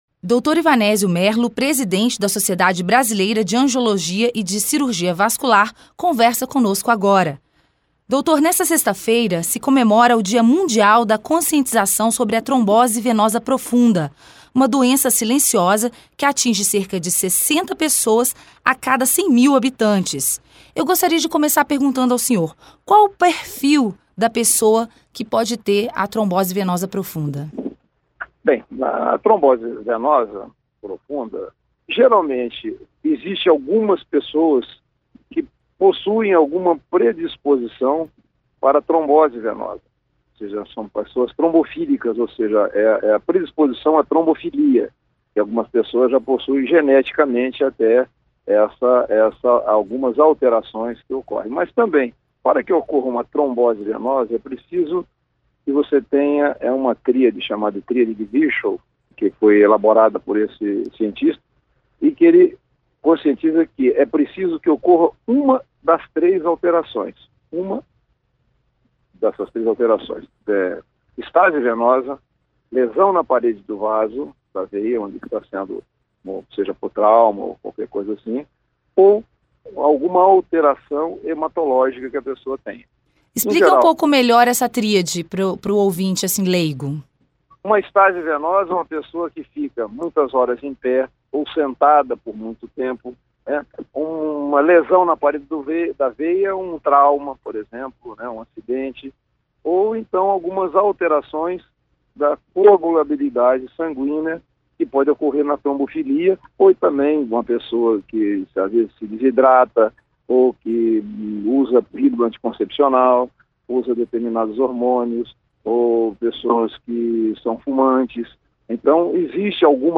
Médico angiologista esclarece dúvidas sobre trombose venosa profunda
A trombose venosa profunda ocorre, principalmente, nas pernas, com a formação de um coágulo que pode se mover pelo corpo e causar embolia pulmonar. Acompanhe a entrevista